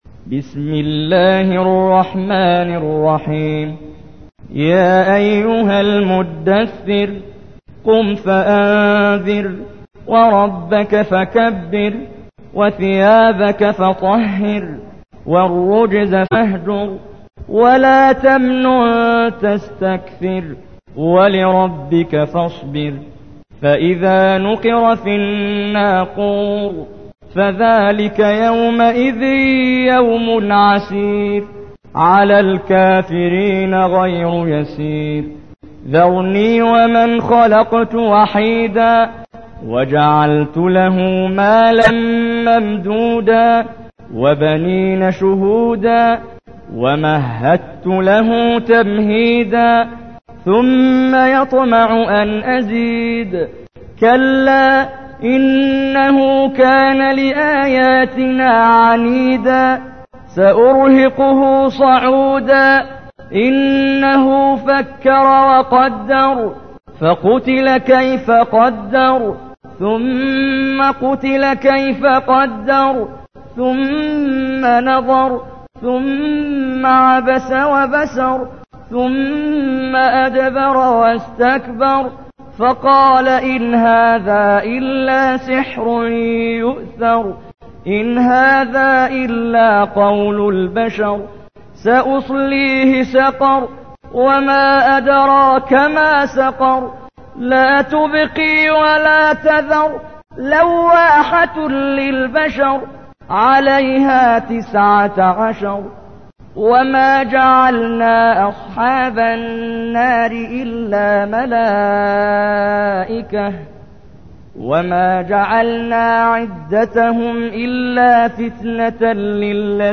تحميل : 74. سورة المدثر / القارئ محمد جبريل / القرآن الكريم / موقع يا حسين